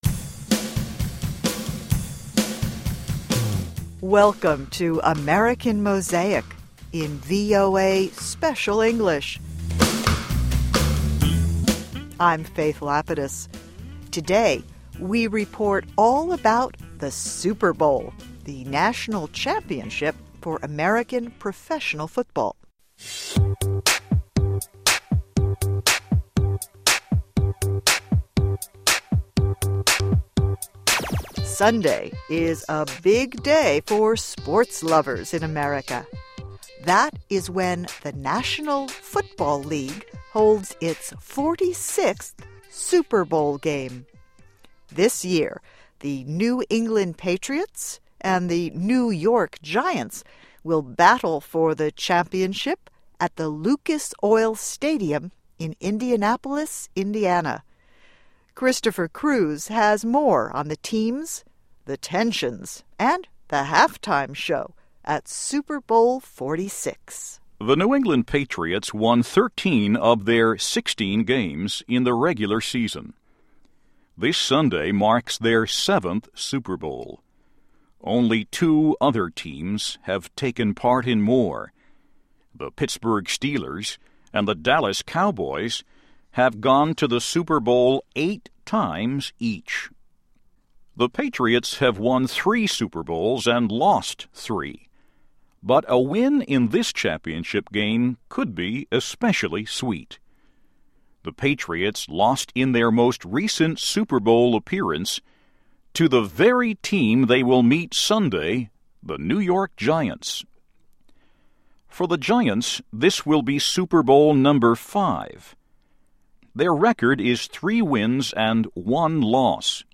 (MUSIC)